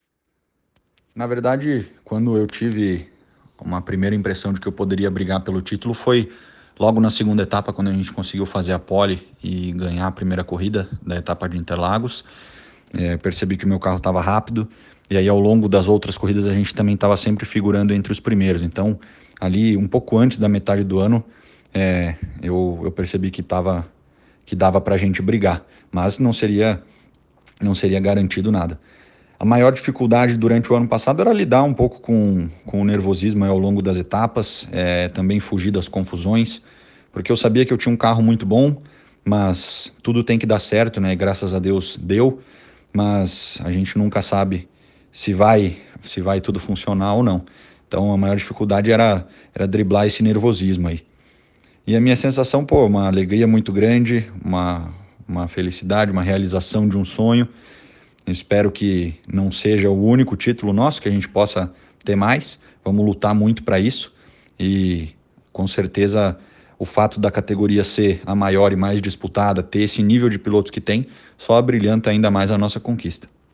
O jovem piloto Gabriel Casagrande, que é sempre pronto a nos atender, também falou ao jornal sobre sua vitória de 2021 e nos trouxe um pouco do gostinho de ser campeão. Você pode ouvir as respostas do piloto no áudio abaixo.
Aduio-Gabriel-Casagrande-Respostas-Materia.ogg